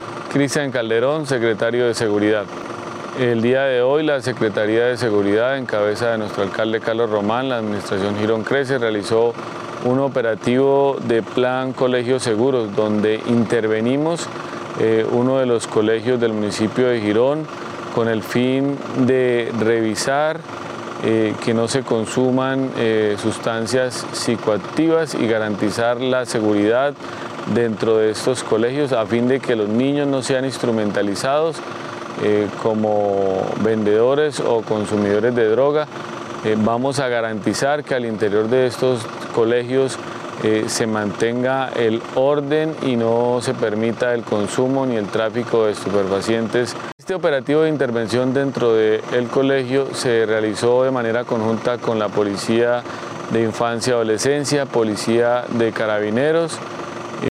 Cristian Calderón - Secretario de Seguridad y Gestión del Riesgo.mp3